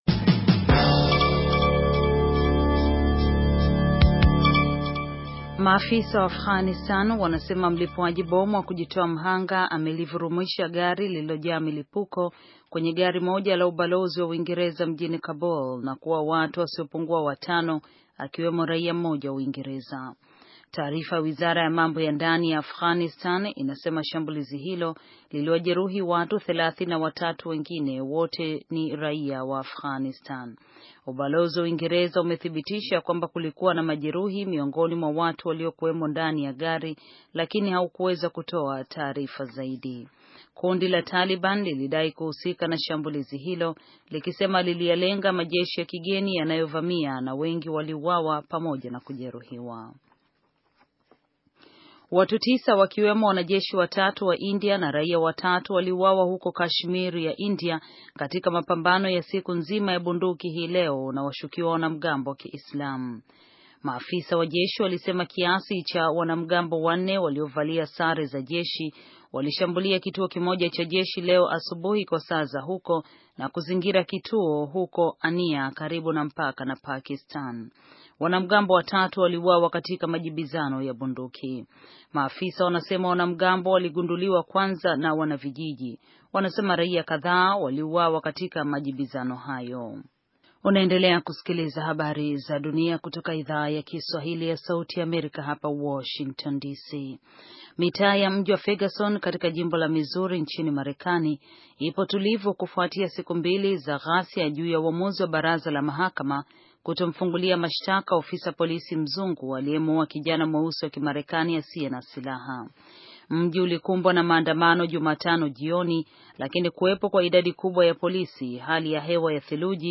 Taarifa ya habari - 4:29